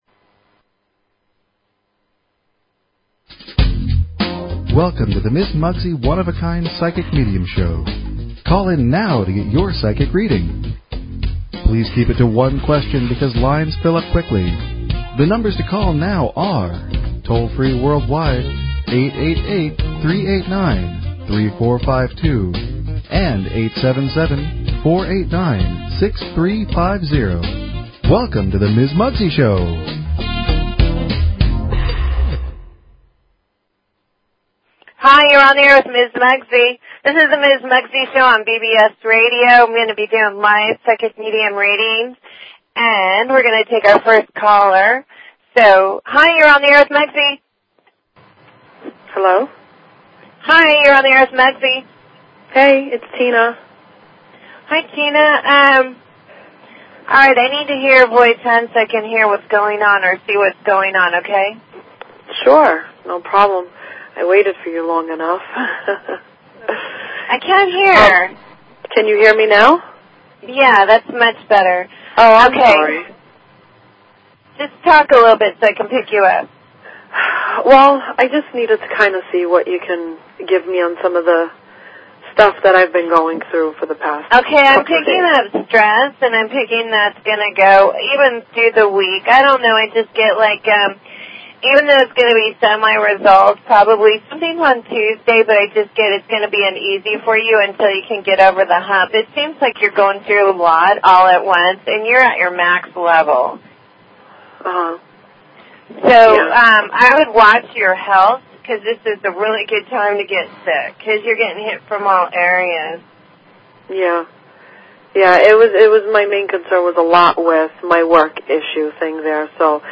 Talk Show Episode, Audio Podcast, One_of_a_Kind_Psychic_Medium and Courtesy of BBS Radio on , show guests , about , categorized as